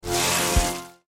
File:Giant mutant spiders roar.mp3
Giant_mutant_spiders_roar.mp3